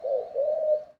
bird_pigeon_call_02.wav